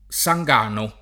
Sangano [ S a jg# no ]